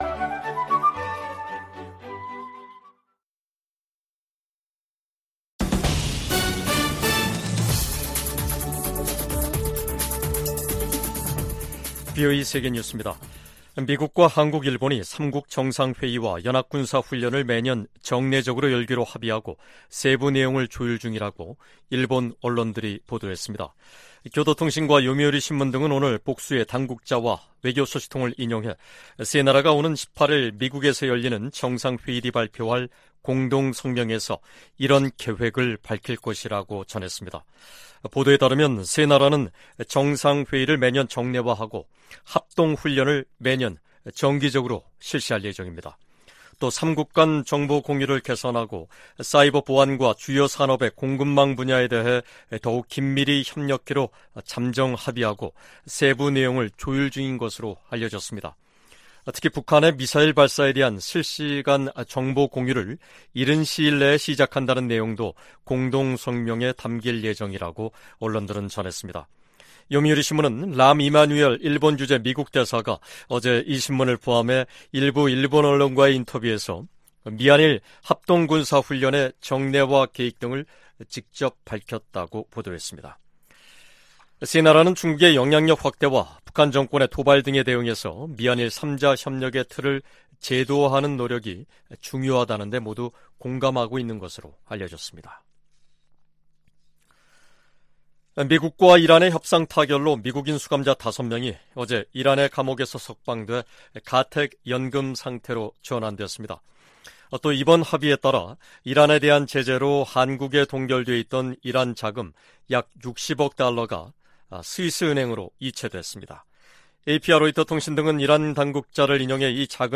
VOA 한국어 간판 뉴스 프로그램 '뉴스 투데이', 2023년 8월 11일 2부 방송입니다. 미국과 한국·일본 등이 유엔 안보리에서 북한 인권 문제를 공개 논의를 요청했습니다. 미 국방부가 북한의 추가 도발 가능성과 관련해 한국·일본과 긴밀하게 협력하고 있다고 밝혔습니다. 북한 해킹조직이 탈취한 미국 내 암호화폐 자산을 동결 조치할 것을 명령하는 미 연방법원 판결이 나왔습니다.